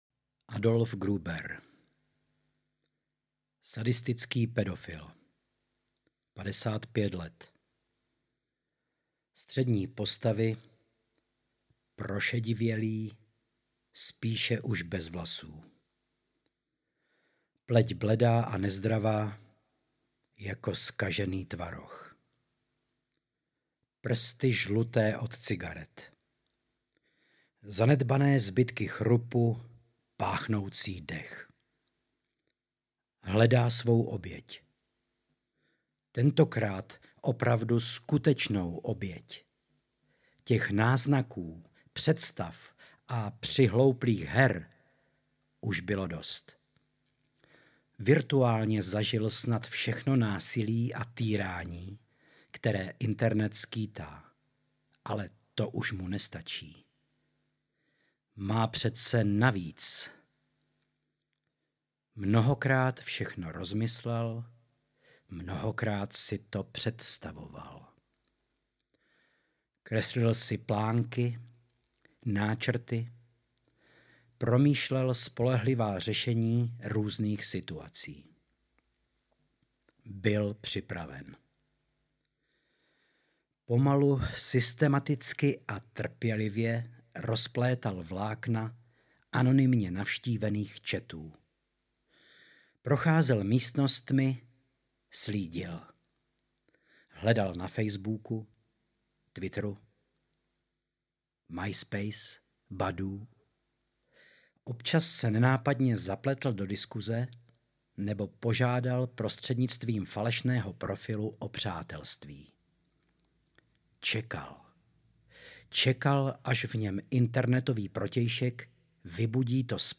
Autorské čtení
autorske-cteni.mp3